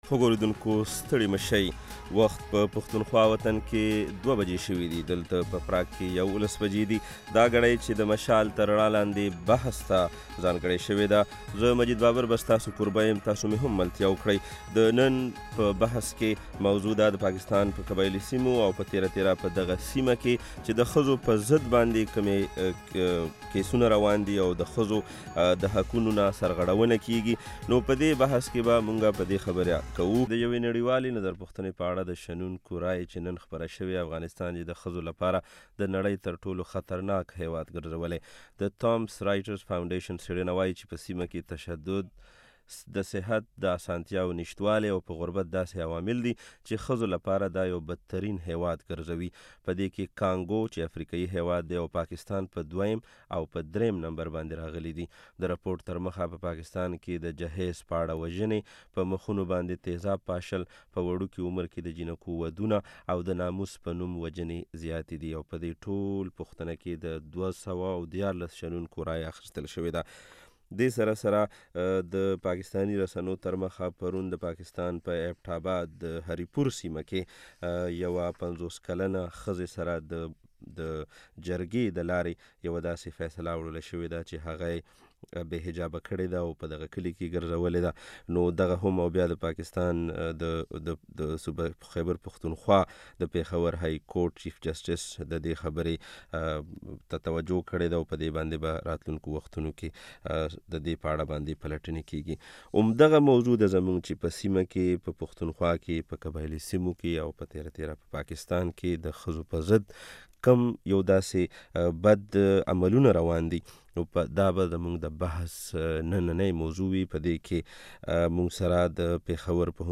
د مشال بحث واوری